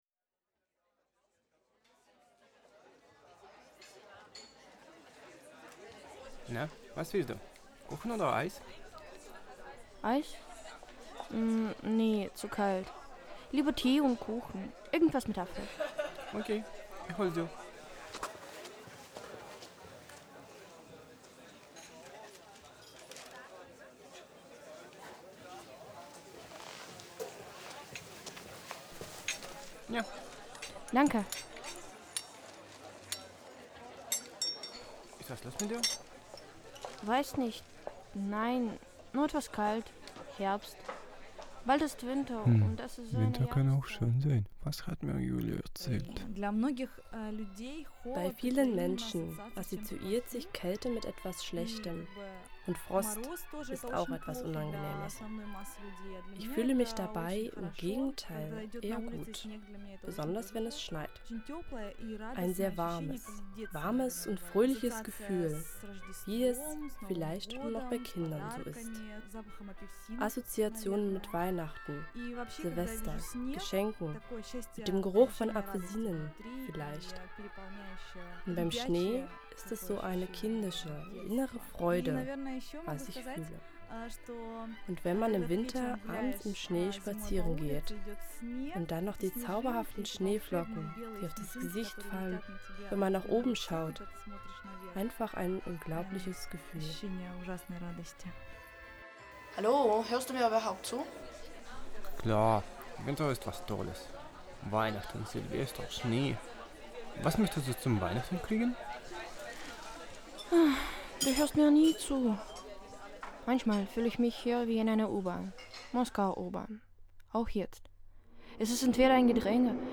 Ein Hörspiel für zwei Personen mit darin integrierten Interviews. Die Geschichte zeigt unterschiedliche Befindlichkeiten und Gegebenheiten von Kälte – emotionale und soziale.